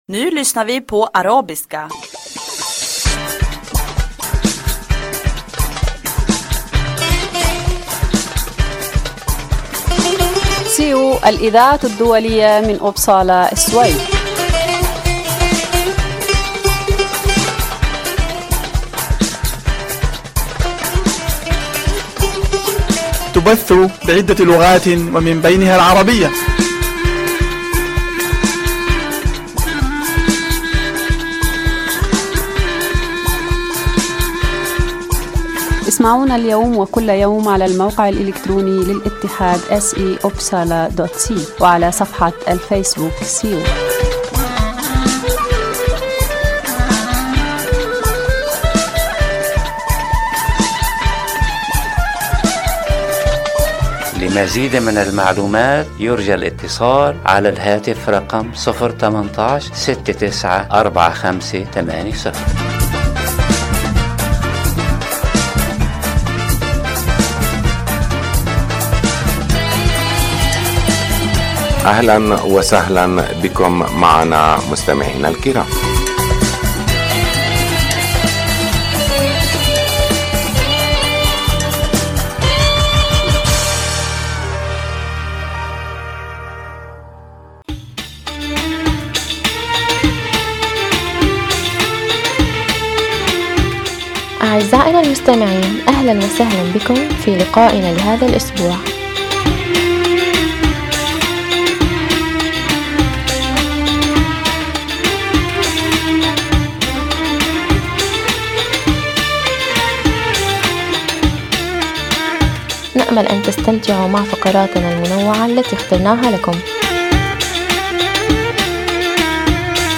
يبث البرنامج العربي من الساعة السابعة إلى السابعة و النصف كل يوم أحد من كل أسبوع. يتضمن البرنامج أخبار من مدينة إبسالا و السويد تهم المهاجرين و برامج ترفيهية و مفيدة أخرى. برنامج هذا الأسبوع يتضمن أخبار الاتحاد السيو، من أخبارنا المحلية و مقتطفات من الصحف العربية و أخبار متنوعة و من الشعر و الموسيقى .